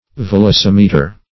Search Result for " velocimeter" : The Collaborative International Dictionary of English v.0.48: Velocimeter \Vel`o*cim"e*ter\, n. [L. velox, -ocis, rapid + -meter.]